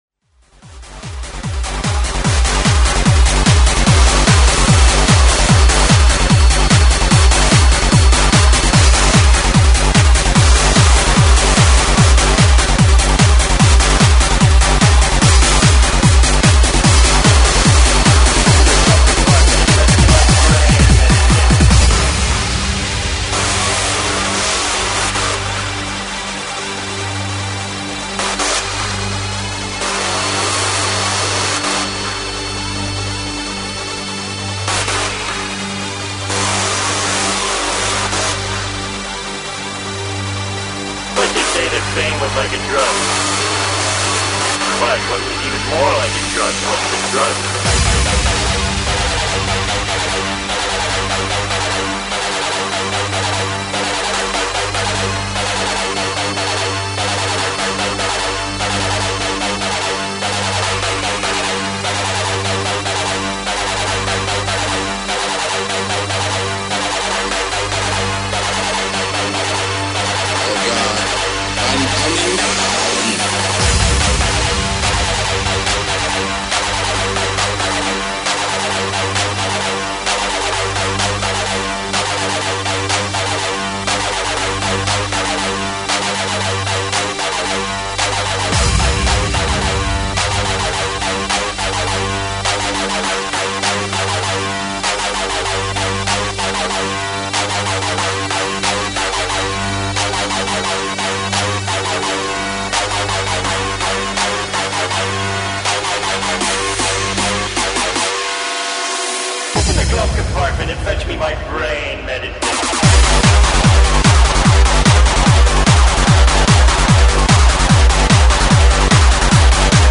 Hard House/Hard Trance/Freeform